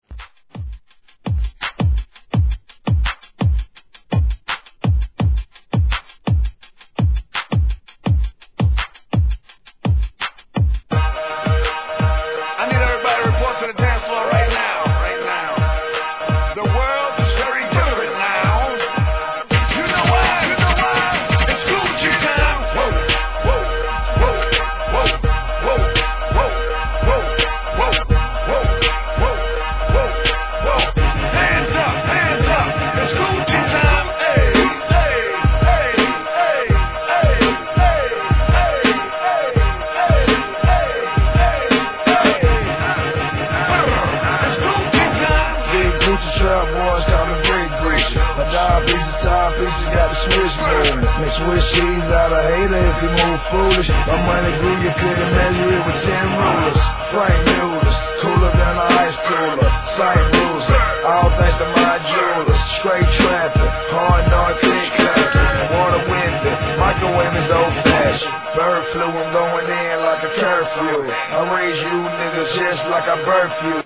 HIP HOP/R&B
[BPM83]  A3.